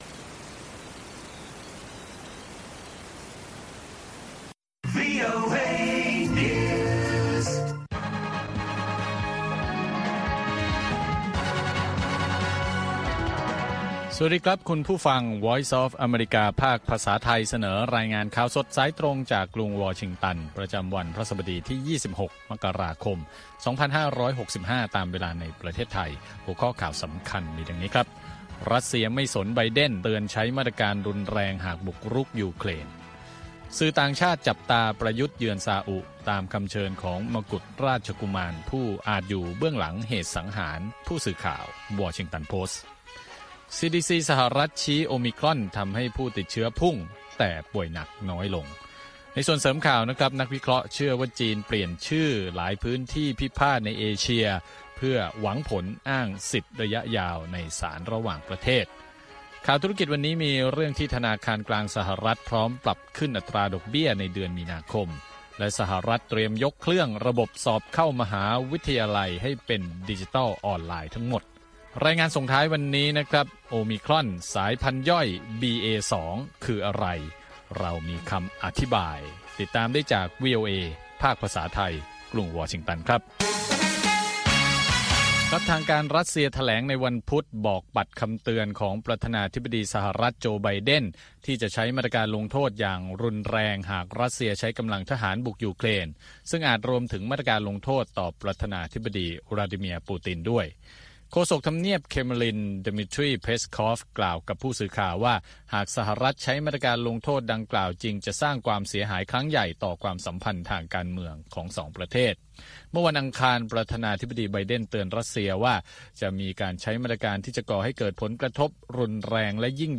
ข่าวสดสายตรงจากวีโอเอ ภาคภาษาไทย 6:30 – 7:00 น. ประจำวันพฤหัสบดีที่ 27 มกราคม 2565 ตามเวลาในประเทศไทย